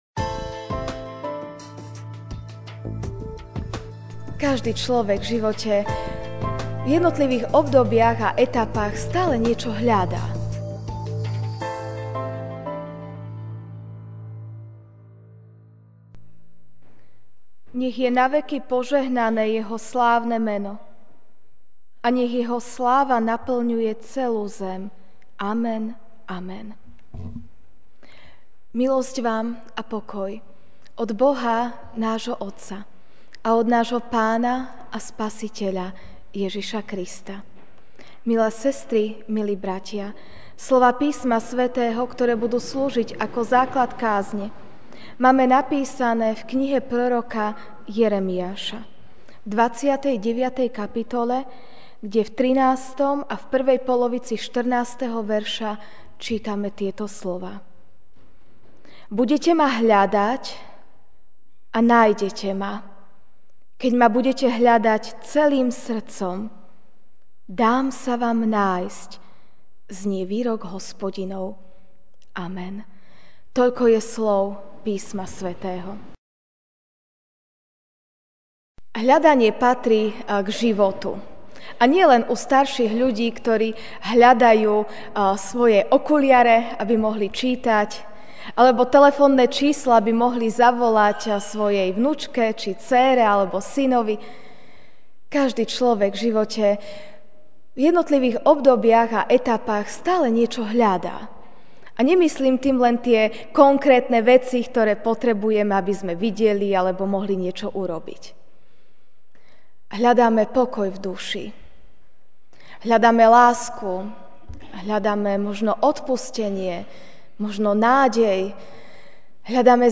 jan 06, 2017 Hľadanie MP3 SUBSCRIBE on iTunes(Podcast) Notes Sermons in this Series Kázeň: Hľadanie (Jer. 29, 13-14a) Budete ma hľadať, a nájdete ma; keď ma budete hľadať celým srdcom, dám sa vám nájsť - znie výrok Hospodinov.